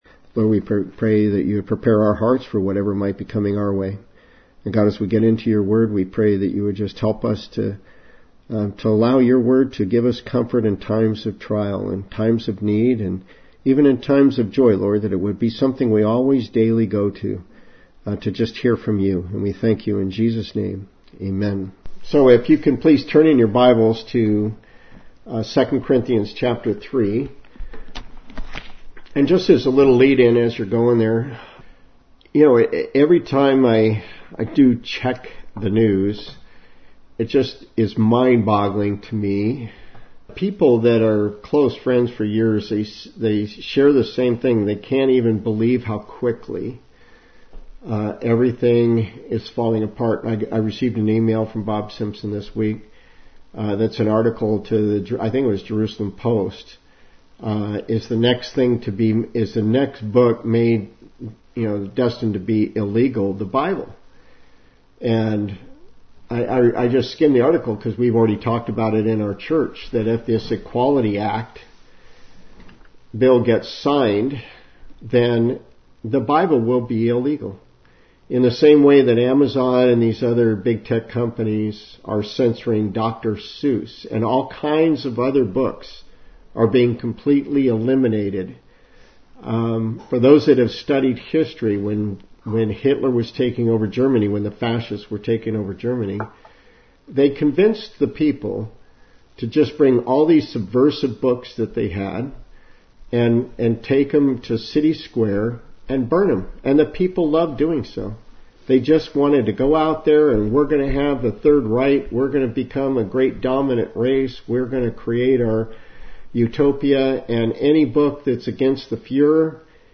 This week we are breaking away from our Revelation study for this topical sermon during a virtual service.